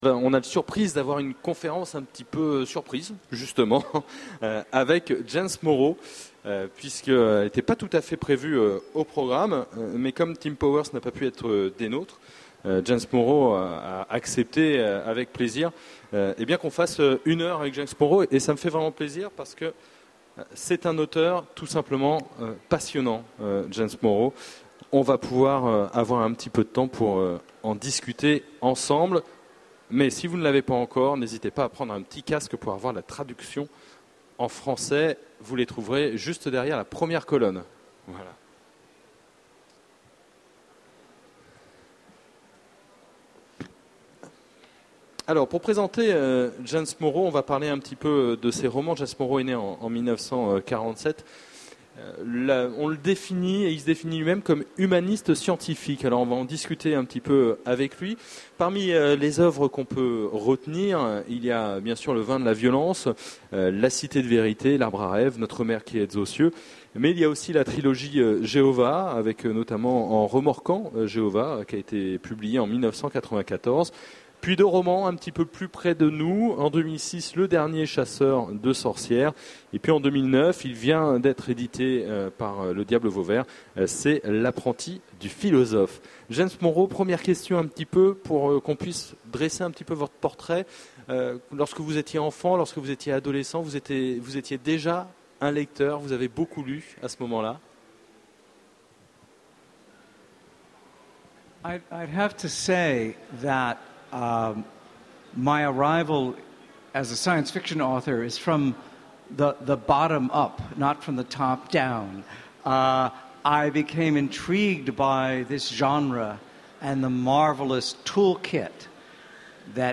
Utopiales 2011 : rencontre avec James Morrow
Télécharger le MP3 à lire aussi James Morrow Genres / Mots-clés Rencontre avec un auteur Conférence Partager cet article